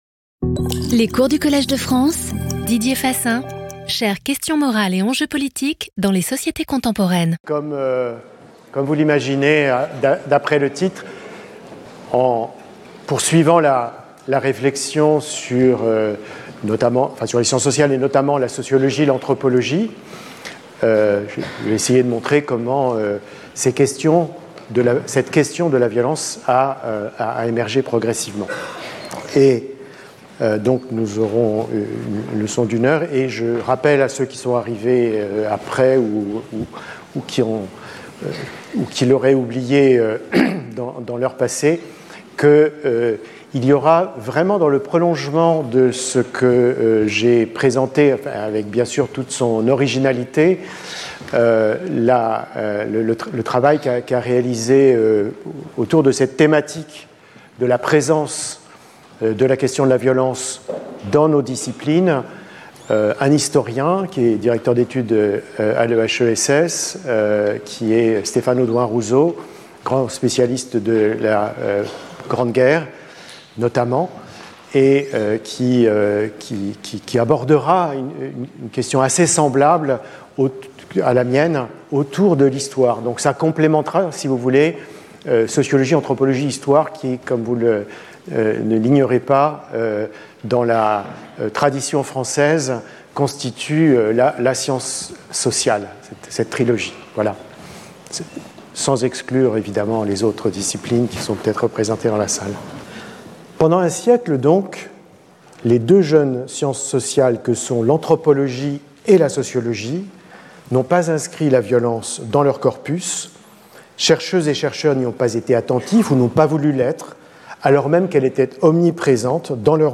Lecture audio
Didier Fassin Professeur du Collège de France